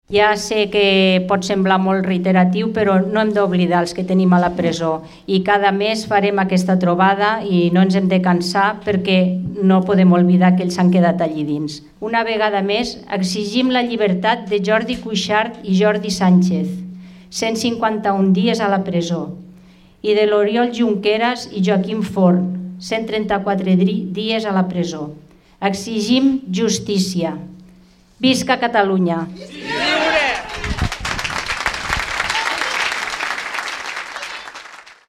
Per aquest motiu, un centenar de caleros van sortir al carrer per manifestar-se en contra d’aquesta mesura, i per reclamar-ne la seva llibertat immediata i també la de la resta de presos polítics.